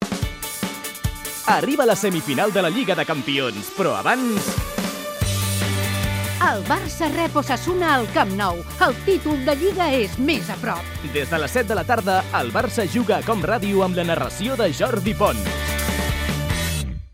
Promoció de la transmissió del partit de la lliga masculina de futbol Futbol Club Barcelona-Osasuna